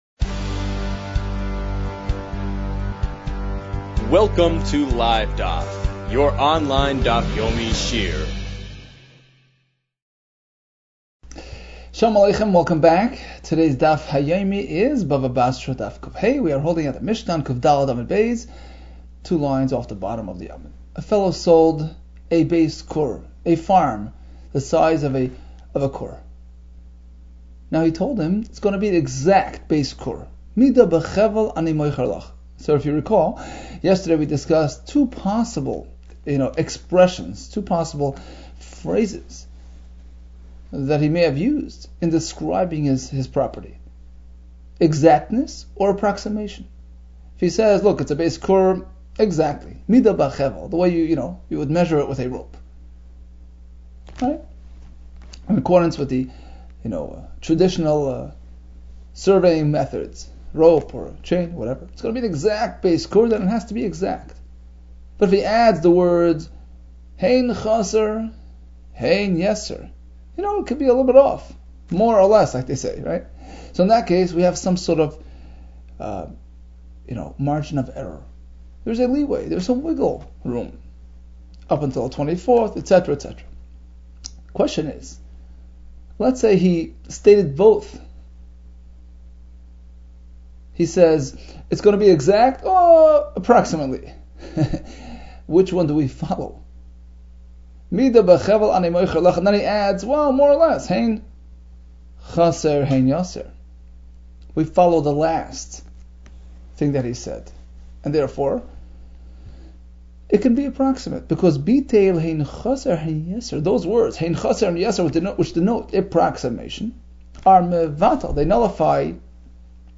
Bava Basra 104 - בבא בתרא קד | Daf Yomi Online Shiur | Livedaf